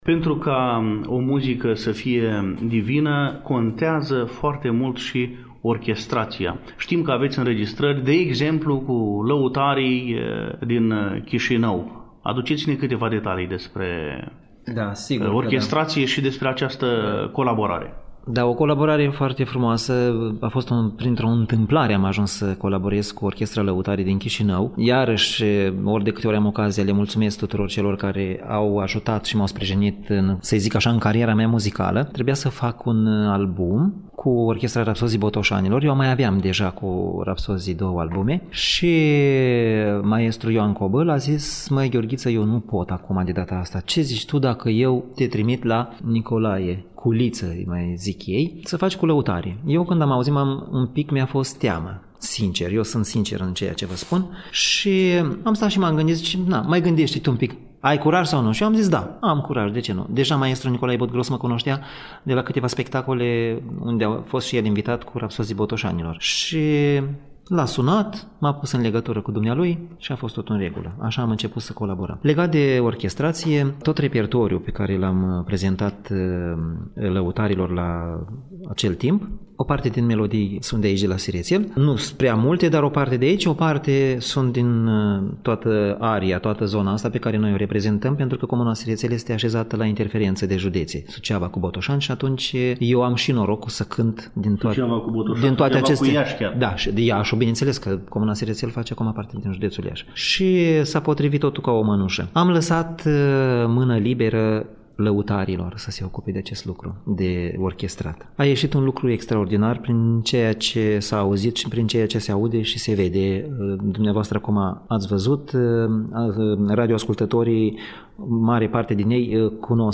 Despre frumoasele proiecte realizate, acum patru ani, în comuna Sirețel ne conturează în interviul acordat implicarea sătenilor în susținerea și realizarea materialelor care reflectă tradițiile, obiceiurile, frumusețea cântului de pe aceste meleaguri.